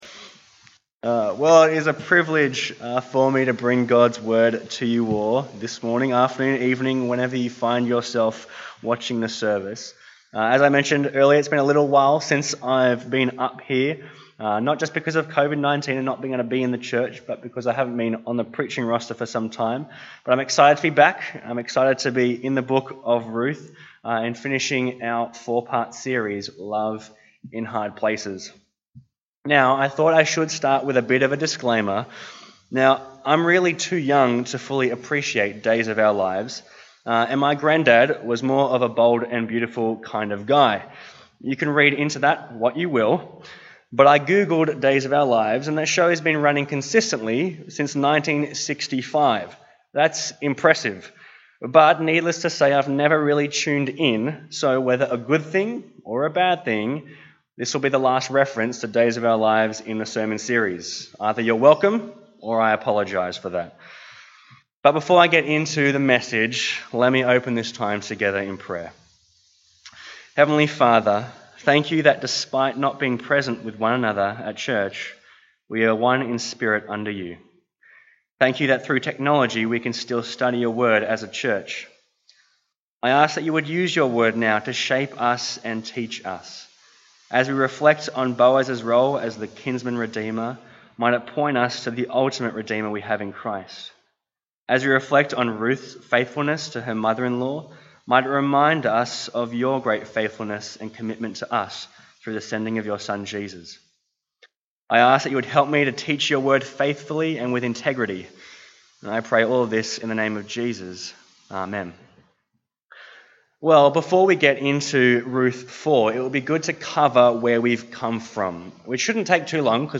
Bible Text: Ruth 4 | Preacher